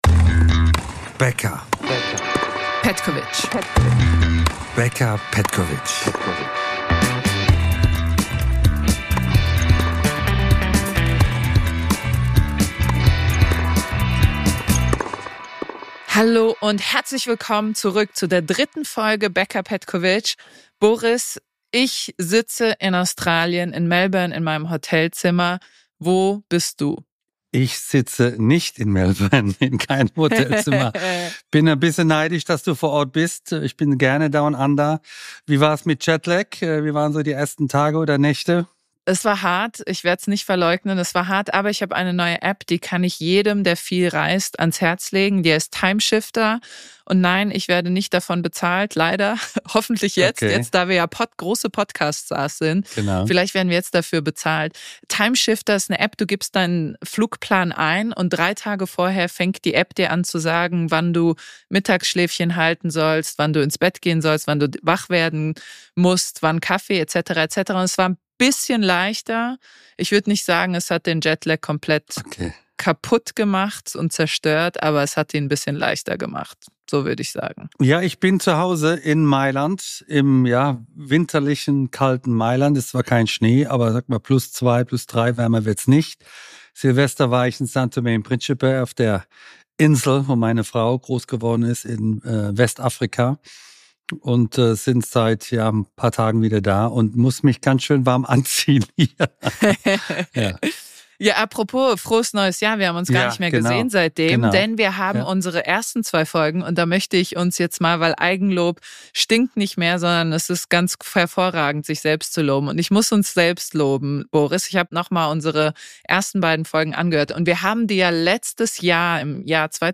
Es ist der Tennispodcast, auf den ihr gewartet habt – und der einzige, den ihr ab sofort noch braucht: Boris Becker und Co-Moderatorin Andrea Petkovic diskutieren alle Highlights der Saison.